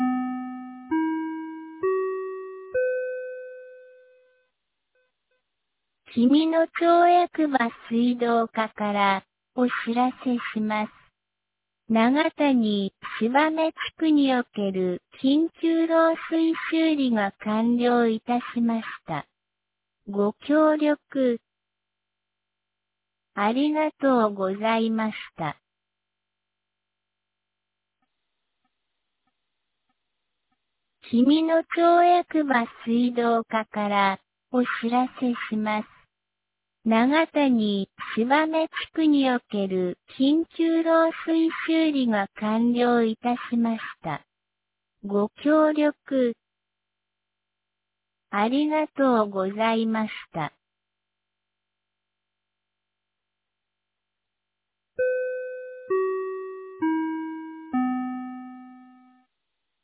2025年11月12日 12時13分に、紀美野町より東野上地区へ放送がありました。